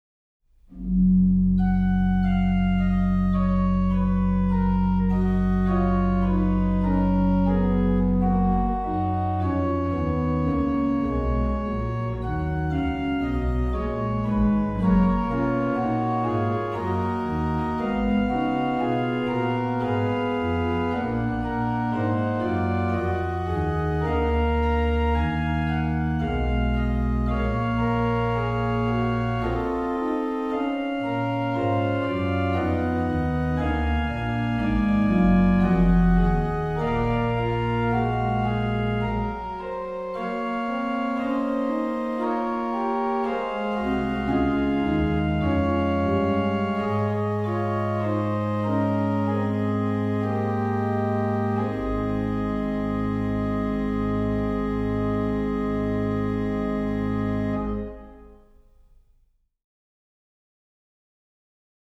Subtitle   in Canone alla Duodecima; à 2 Clav. et Ped.
Registration   rh: POS: Pr4 (8ve lower)
lh: MAN: Pr8
PED: Sub16, Oct8